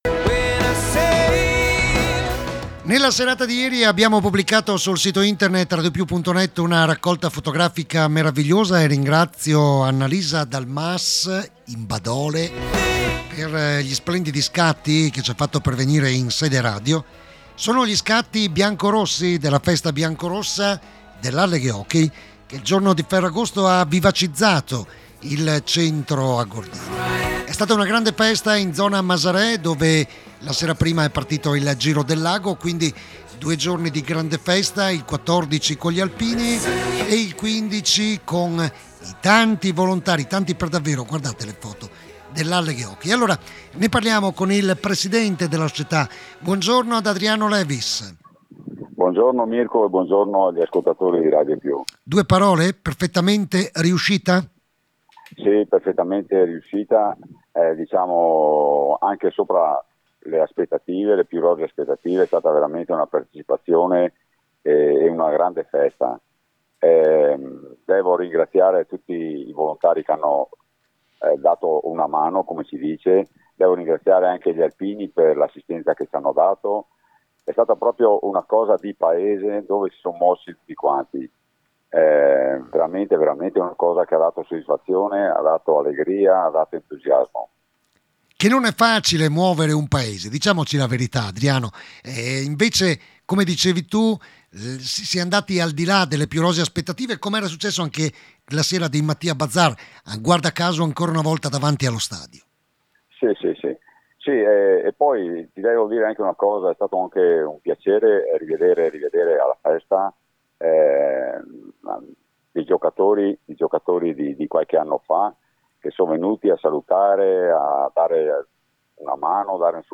diretta RADIO PIU